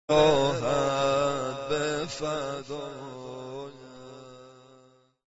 زمینه - شور